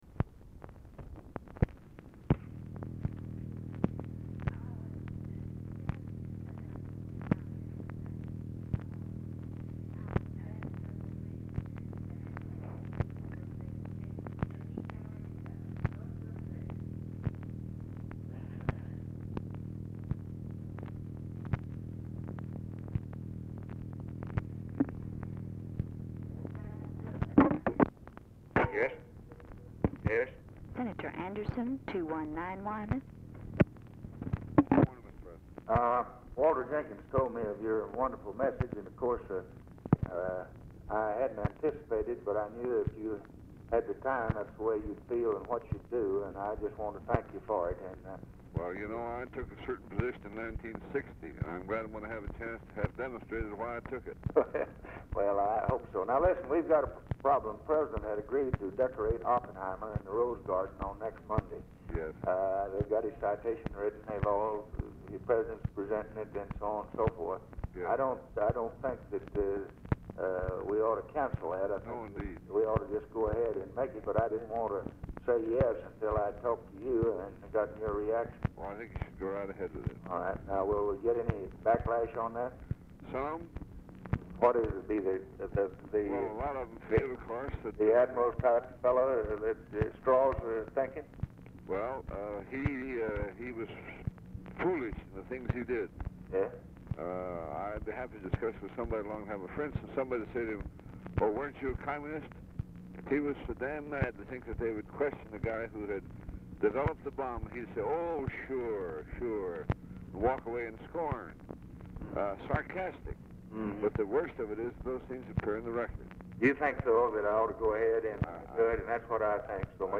INAUDIBLE OFFICE CONVERSATION WHILE ANDERSON IS ON HOLD
Format Dictation belt
Specific Item Type Telephone conversation